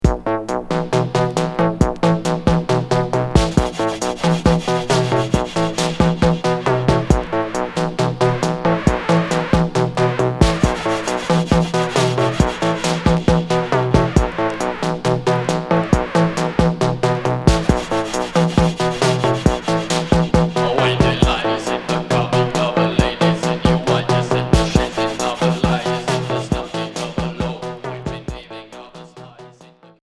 Cold minimal